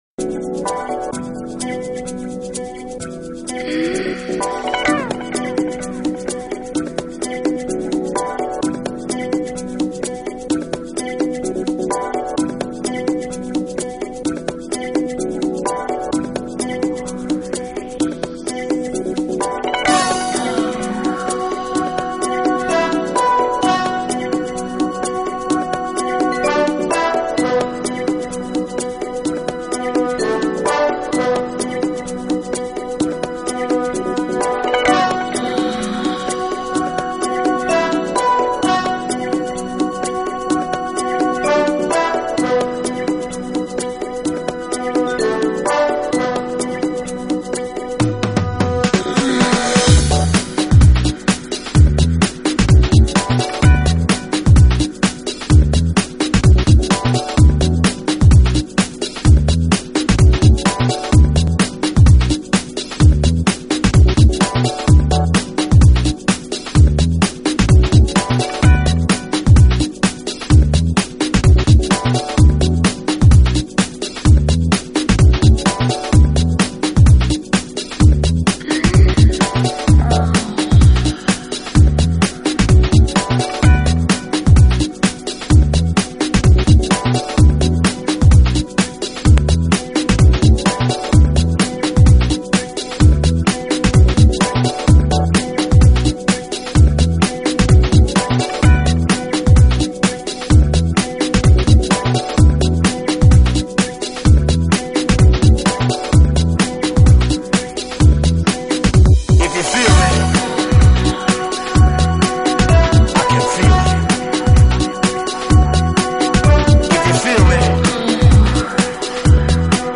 Genre: House, Downtempo, Lounge
得十分悦耳。